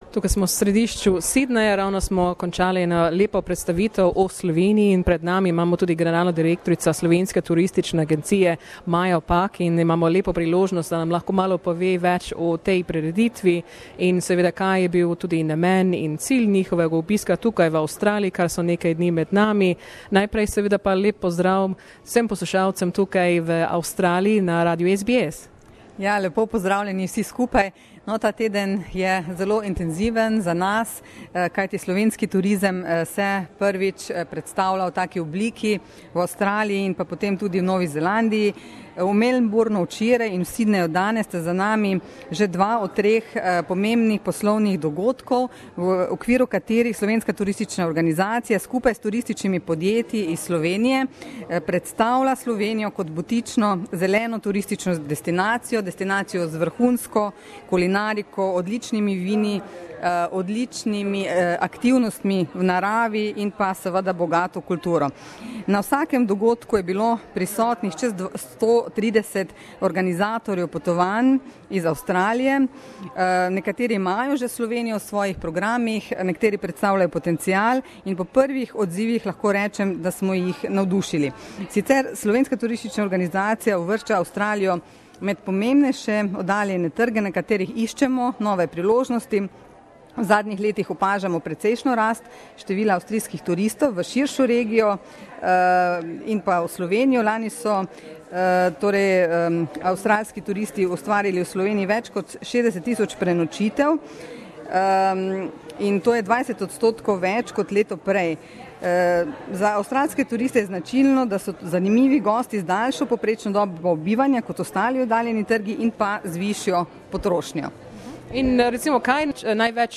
During this week, the Slovenian Tourist Board presented Slovenia to Australians on a larger scale in Melbourne and Sydney through Slovenian tourism workshops. We spoke to one of the key speakers at this event, Director General of the Slovenian Tourist Board Maja Pak.